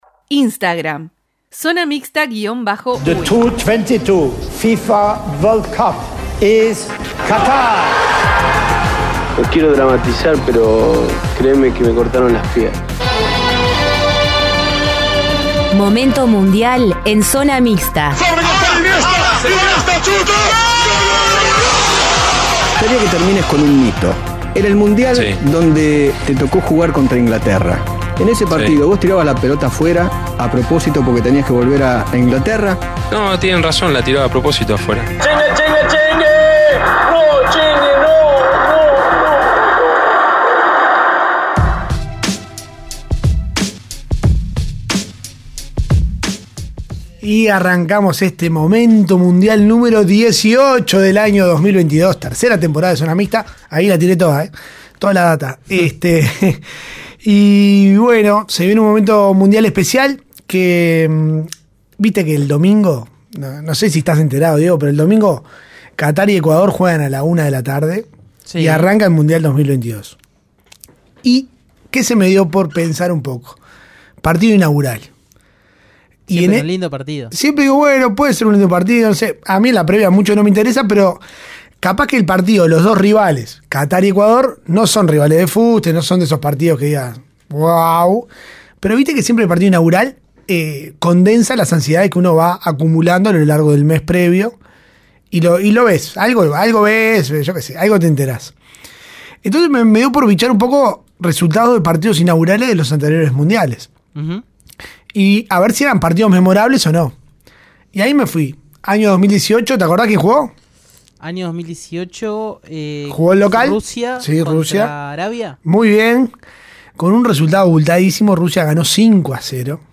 Zona Mixta: entrevista